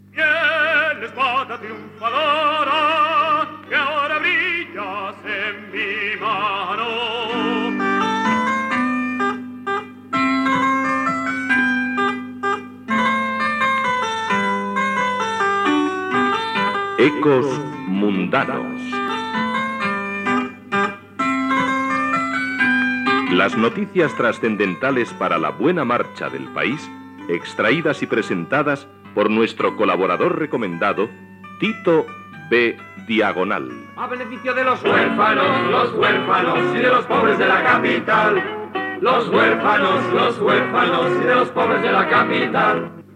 Entreteniment
Fragment de sarsuela